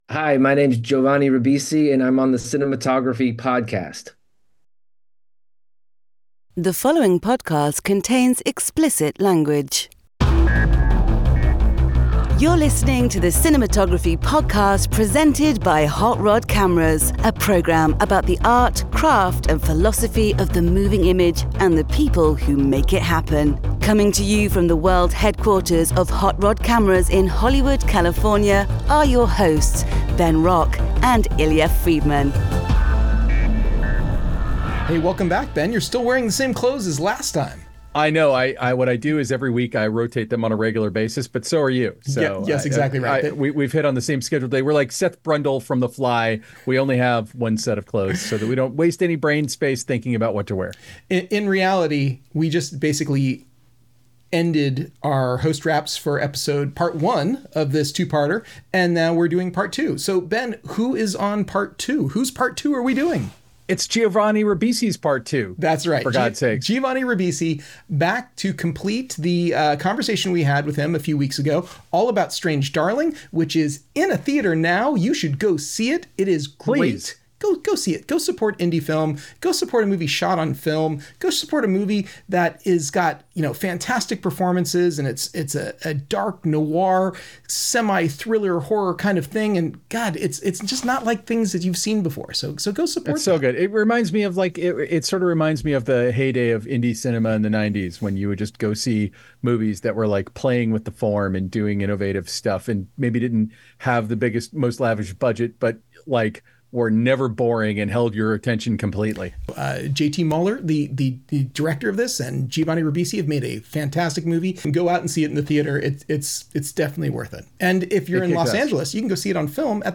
In the second part of our interview with Strange Darling cinematographer Giovanni Ribisi, he delves into his meticulous approach to testing, collaboration with director JT Mollner, and the creative muscles he uses for both acting and cinematography. Strange Darling is a puzzle box of a movie, with seven different chapters mixed up to tell the story.